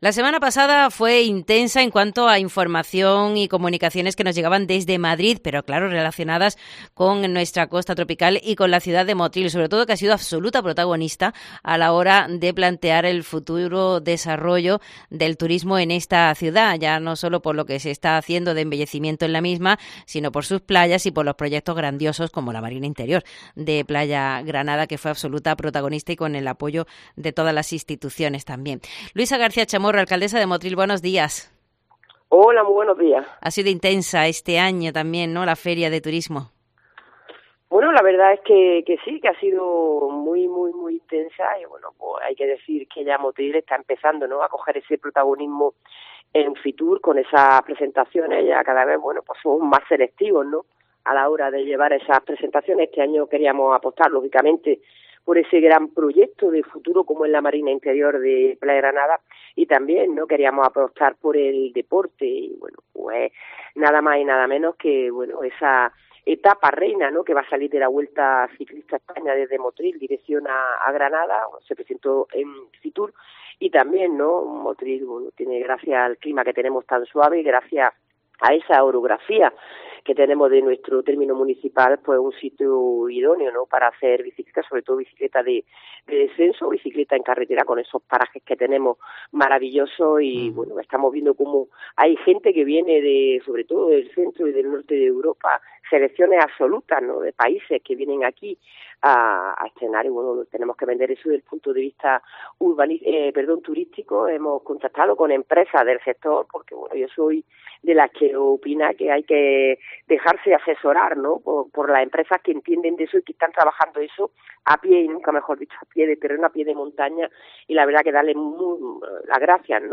Hablamos con la Alcaldesa de Motril, Luisa García Chamorro, y repasamos las presentaciones que han tenido lugar en la Feria Internacional de Turismo de Madrid, FITUR, la semana pasada y donde alcanzó gran protagonismo el proyecto de la Marina Interior de Playa Granada.